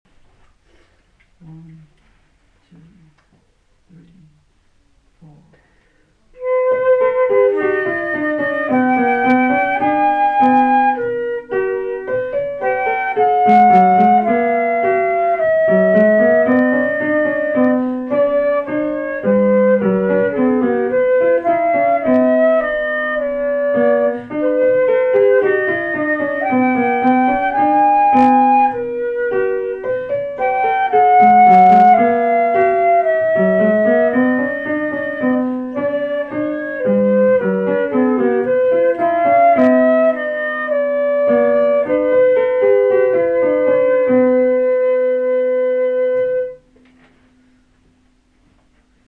This is called a mirror canon.
Here is the Royal theme, played by the flute, and leader played by the piano.
canon3flutepiano.mp3